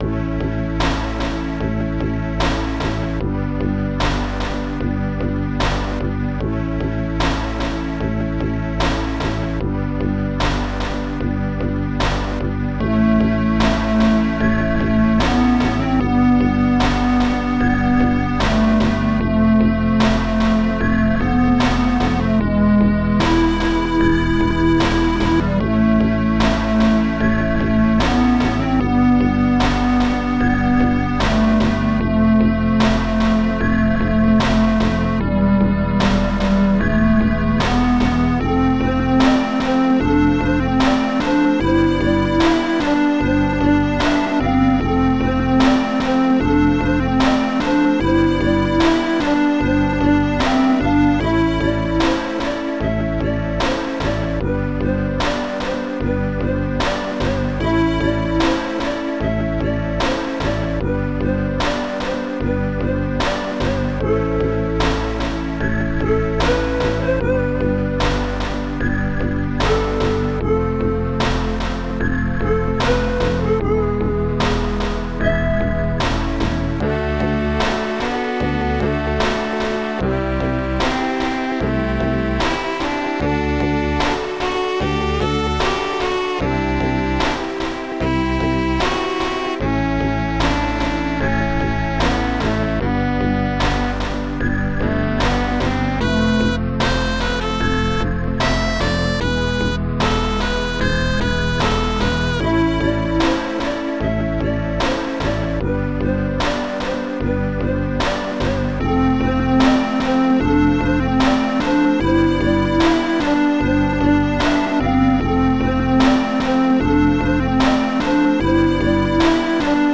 Protracker Module  |  2000-10-02  |  122KB  |  2 channels  |  44,100 sample rate  |  5 minutes, 13 seconds
st-15:fredflute2
st-11:bluesnare
st-14:kydstrings
st-14:kydsax2
st-04:lamertrumpet